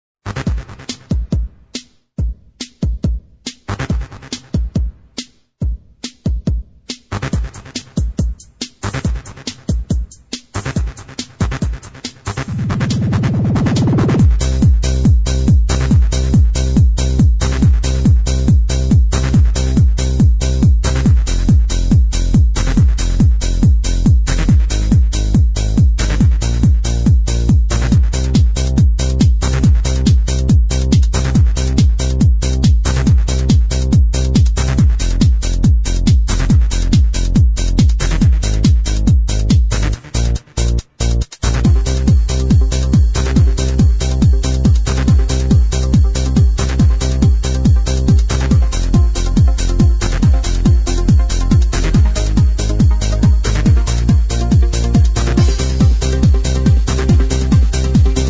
song (original) dated 1994, 5:10 in length